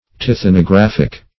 Search Result for " tithonographic" : The Collaborative International Dictionary of English v.0.48: Tithonographic \Ti*thon`o*graph"ic\, a. [Tithonic + -graph + -ic.] Of, relating to, or produced by, the chemical action of rays of light; photographic.